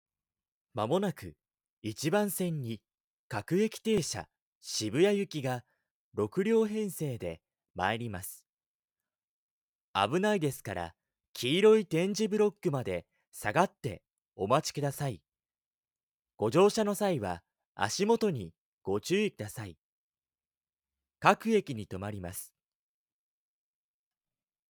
ボイスサンプル
アナウンス放送(鉄道)